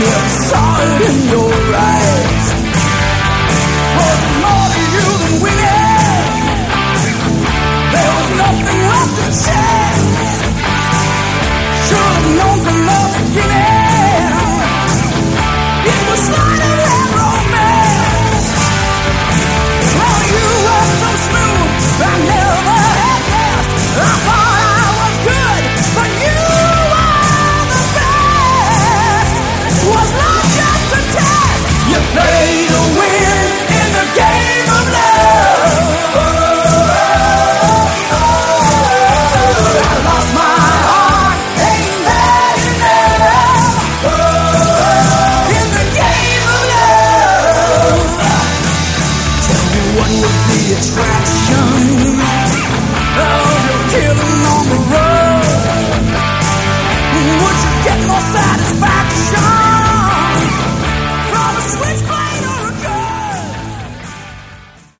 Category: Melodic Hard Rock